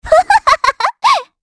Estelle-Vox_Happy2_kr.wav